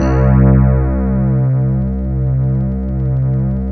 RHODES C1.wav